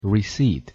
Wymowa zgoła inna niż pisownia. Risiit.
pronunciation_en_receipt.mp3